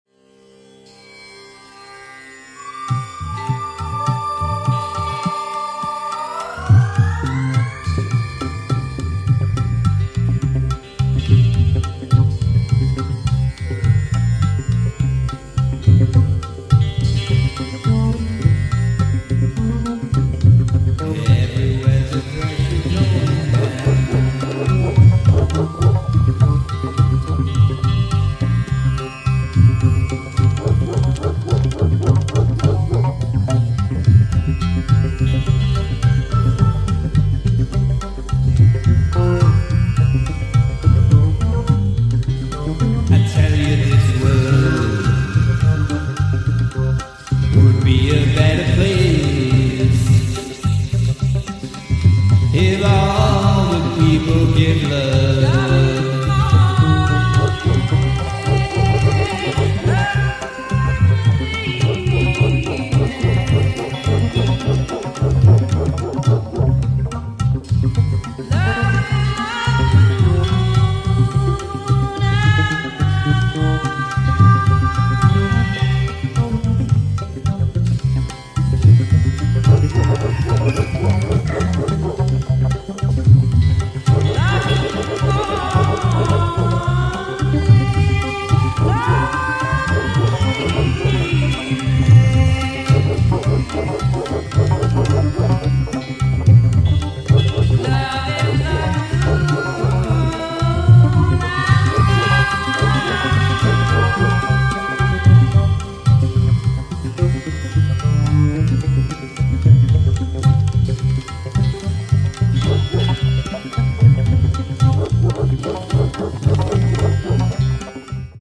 sitar